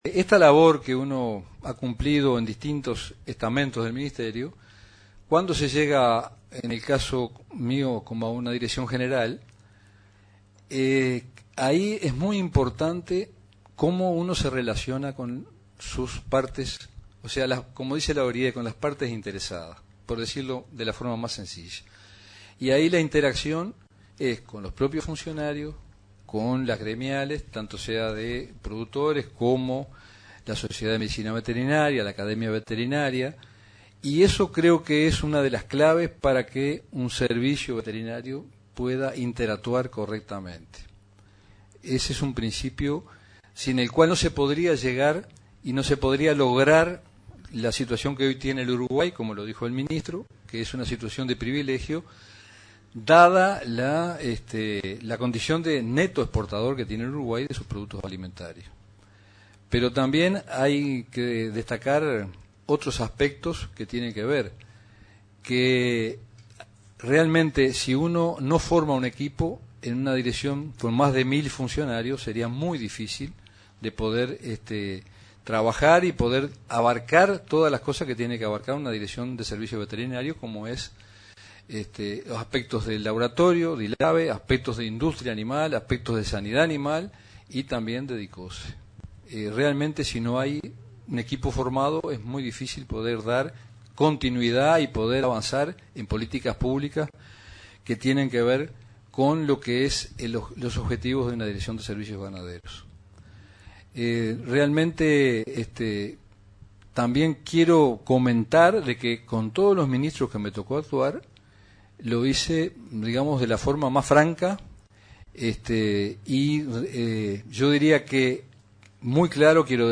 El director saliente de la Dirección General de Servicios Ganaderos del MGAP, Francisco Muzio, destacó este miércoles durante el acto de asunción de su sucesor, Eduardo Barre, que dicha dirección tuvo independencia técnica a la hora de actuar. “Este es un aspecto sumamente importante para que un país no tenga problemas como han tenido otros por una indebida injerencia política en las decisiones sanitarias”, destacó.